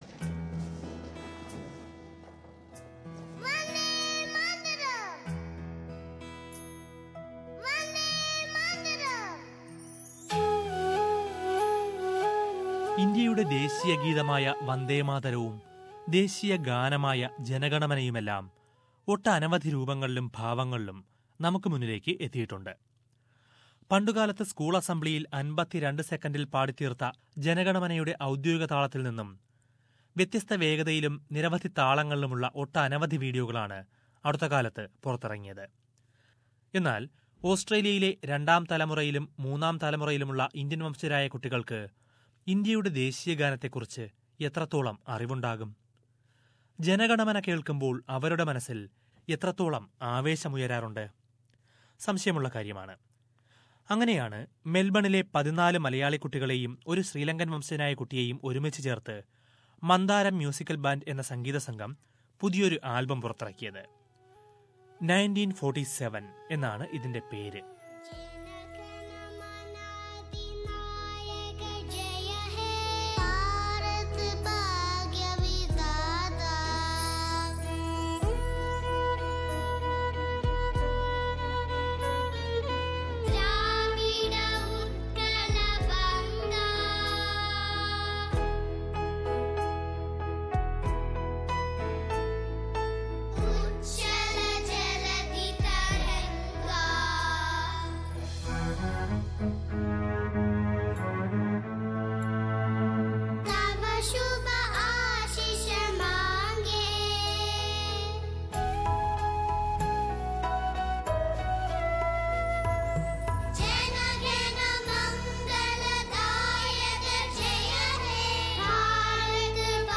Mandaram music band in Melbourne has produced video in tribute to the freedom fighters. Listen to a report about that video named 1947